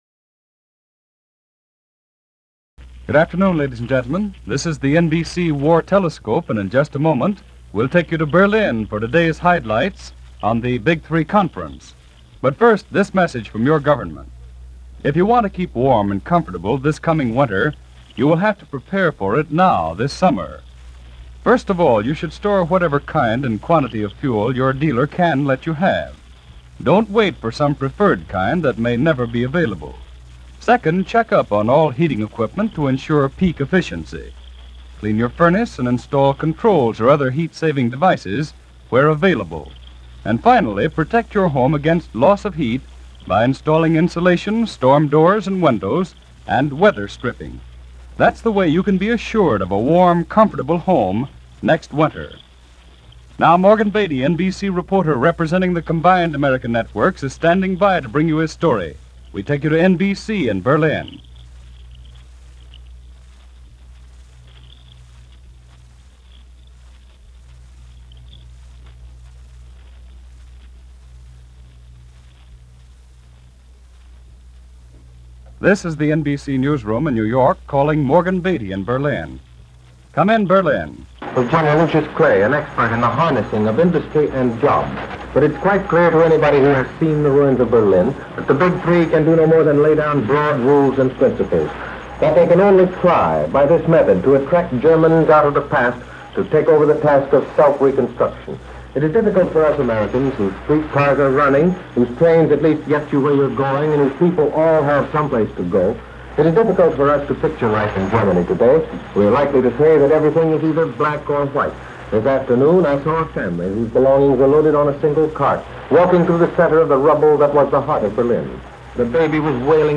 Berlin - Year Zero - July 21, 1945 - news reports from Europe and the Far East from War Telescope - NBC Radio - July 21, 1945.